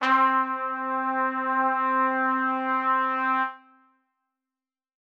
Trumpet Long.wav